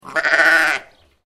جلوه های صوتی
دانلود صدای گوسفند از ساعد نیوز با لینک مستقیم و کیفیت بالا